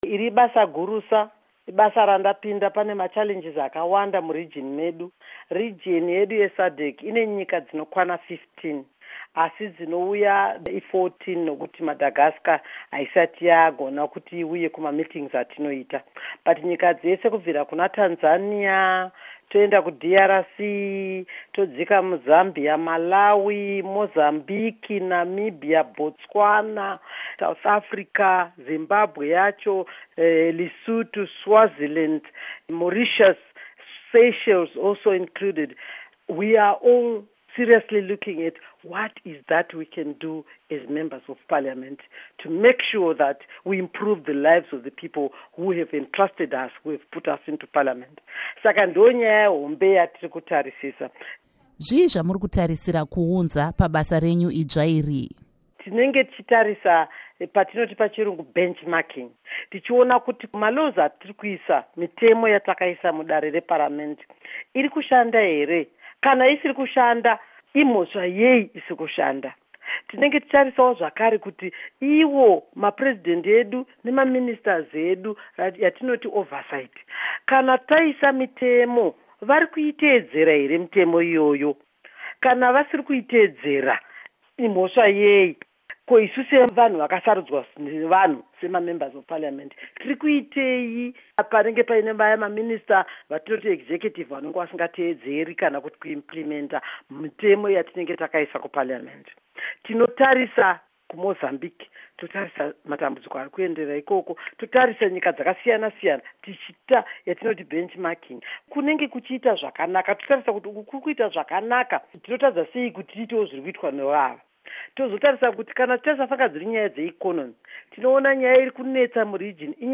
Hurukuro naAmai Monica Mutsvangwa